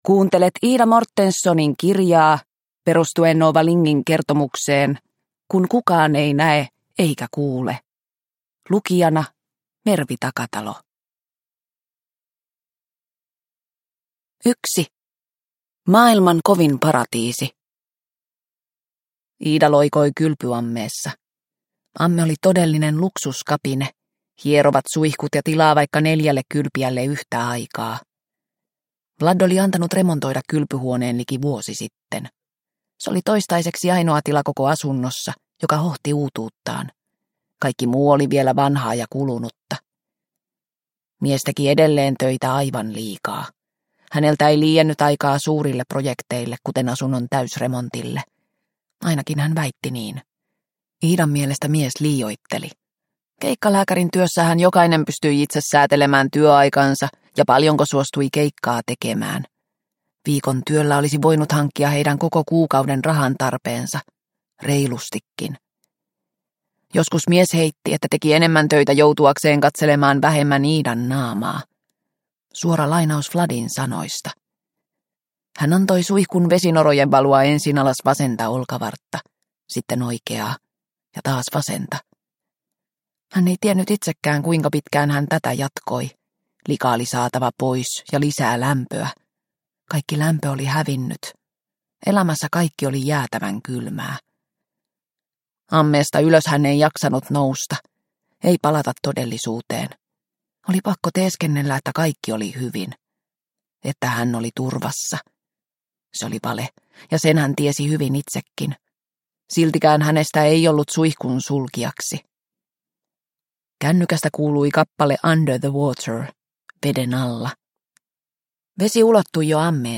Kun kukaan ei näe eikä kuule – Ljudbok – Laddas ner